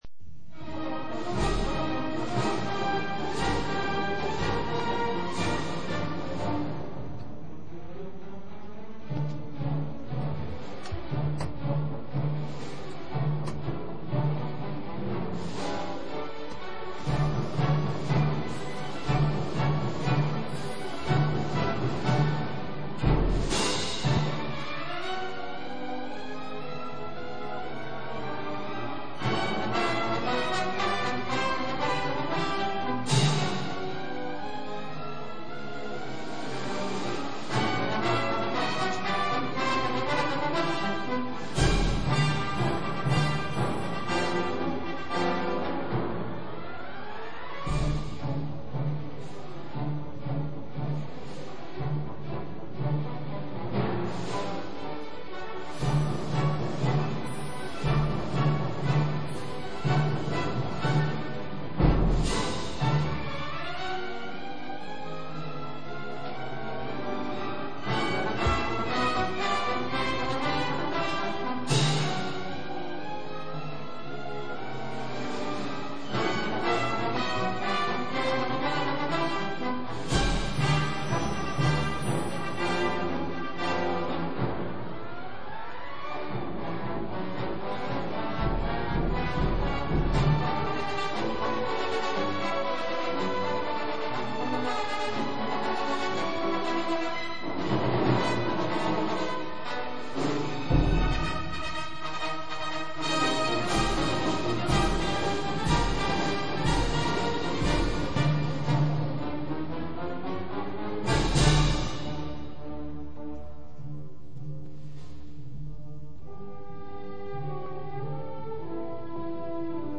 そしてこの度、区民響は1998年6月にオープンしたばかりの横浜みなとみらいホールで1998年7月26日「港北サマーファミリーコンサート」を行う機会に恵まれました。
威風堂々演奏風景
当日は定員約2000名のところ、6000名を超える方々から参加申込をいただき、大多数が入場できない事体になってしまい非常に申し訳なく思っていますが、ここに当日の演奏会のラストを飾ったエルガー作曲 行進曲「威風堂々 第1番（オルガン付）」（上の写真はその演奏風景です）の録音をご用意致しました。同ホールの中継室からMDで録音されたもので、上の写真を撮影した際のシャッター音も入っていますが^^; お楽しみいただけるのではないかと思います。